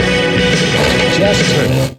120BPMRAD6-R.wav